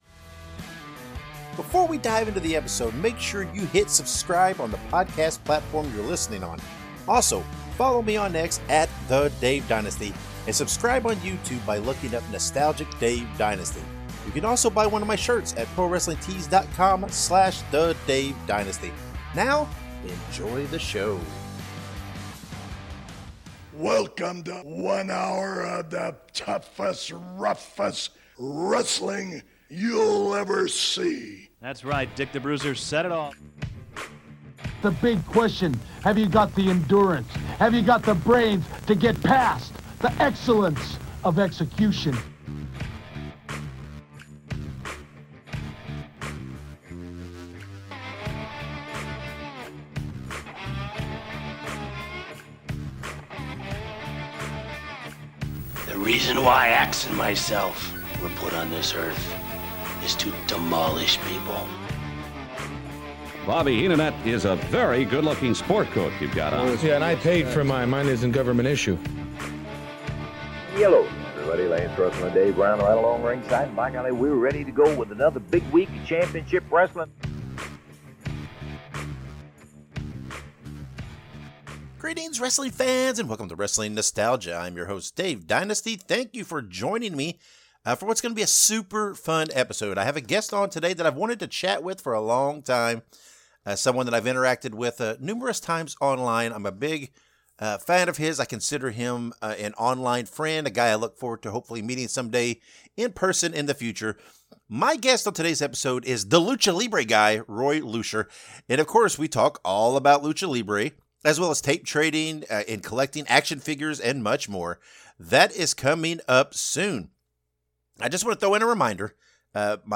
Biblically-Informed Politics Panel